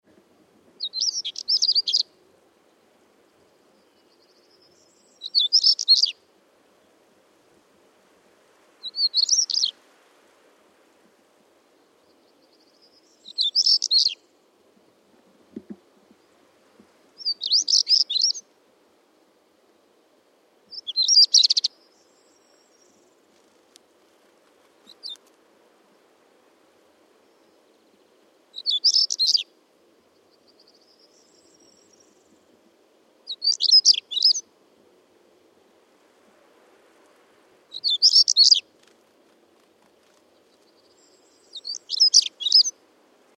Hören Sie sich hier den Gesang des Schwarzkehlchens an.
Schwarzkehlchen1.mp3